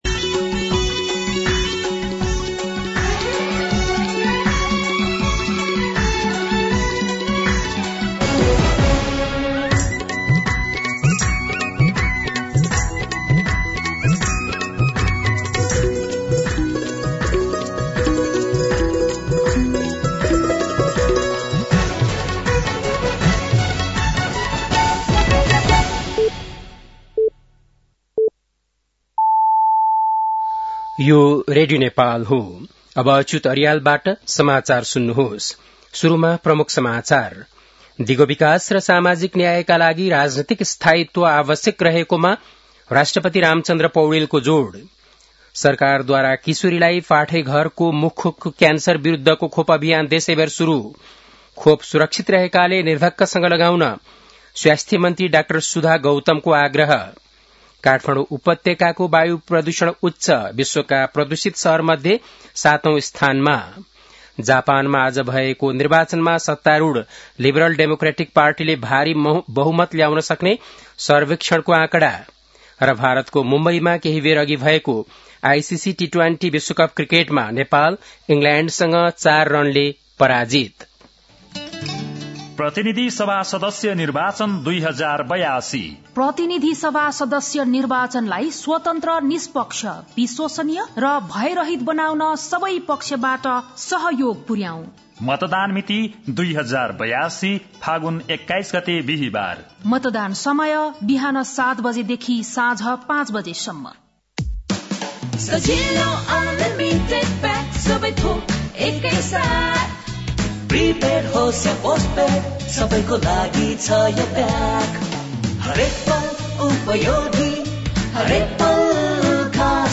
बेलुकी ७ बजेको नेपाली समाचार : २५ माघ , २०८२
7.-pm-nepali-news-1-2.mp3